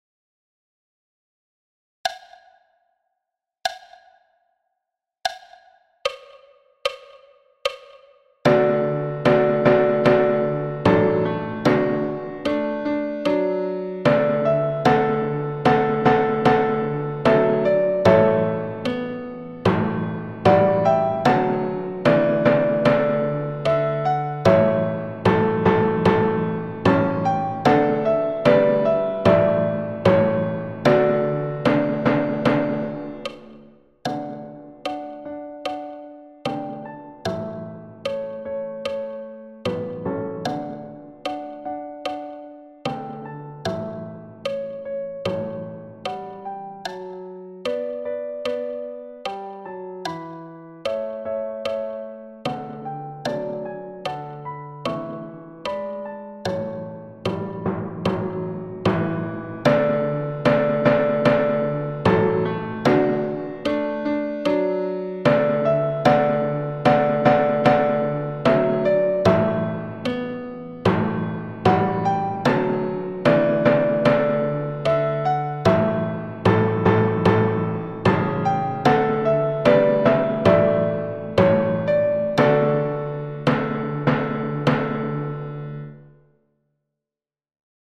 Marche – piano et timbales à 70 bpm
Marche-piano-et-timbales-a-70-bpm.mp3